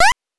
jump_13.wav